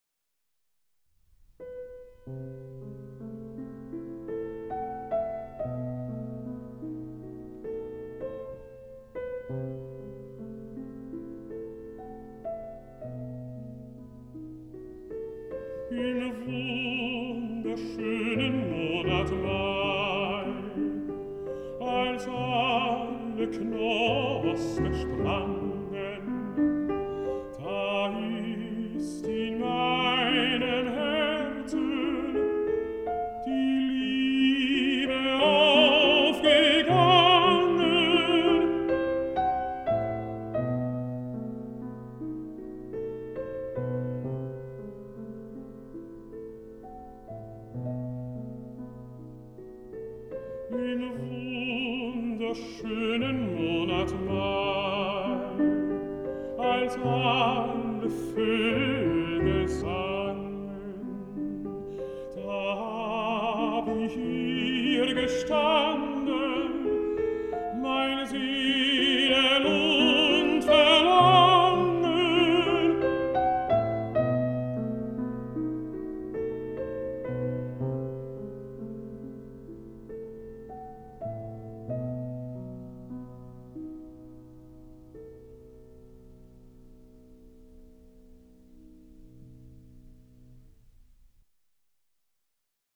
Música vocal
Música clásica